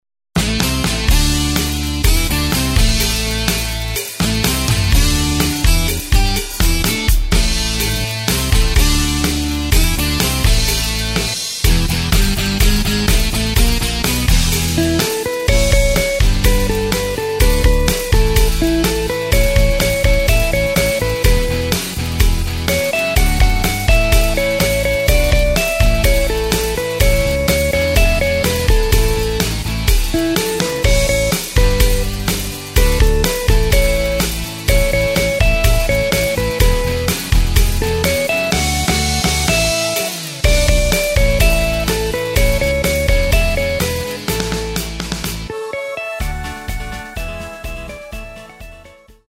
Takt:          2/4
Tempo:         125.00
Tonart:            A
Austropop aus dem Jahr 1983!